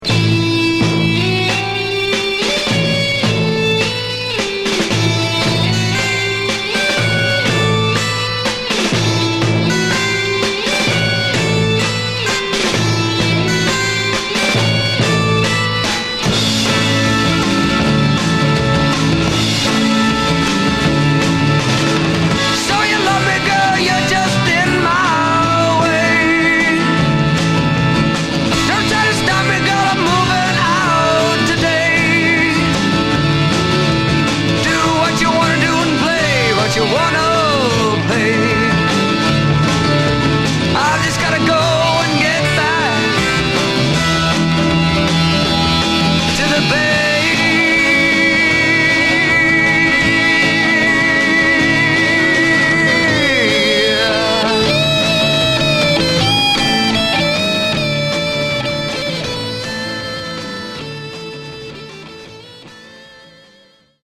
Psych Rock single